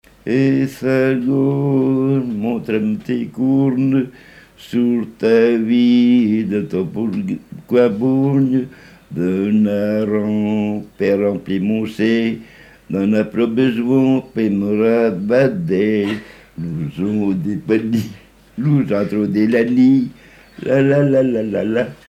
Chant en francoprovençal
Pièce musicale inédite